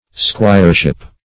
squireship \squire"ship\, n.
squireship.mp3